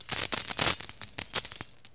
Coins_ADD_Sound.wav